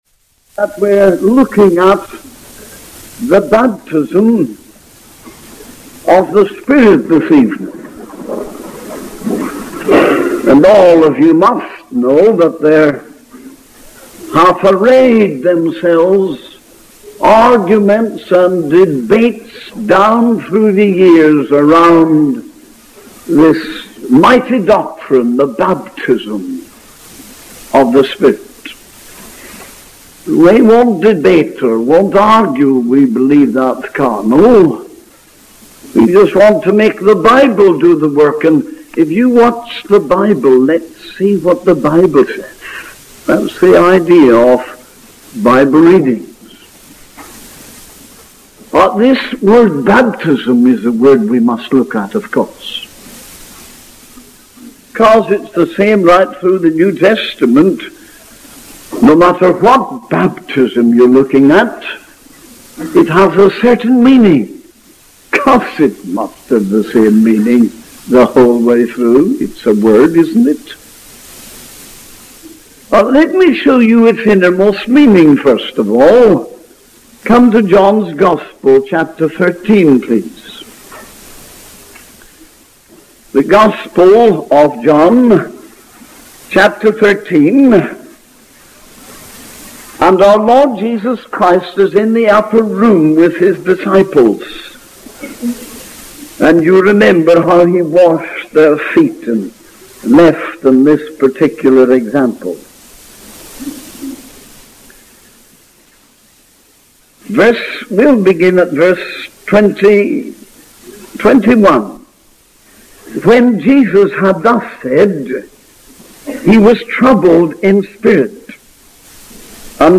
The sermon concludes with a call to understand the true nature of the Spirit's baptism and its implications for the church today.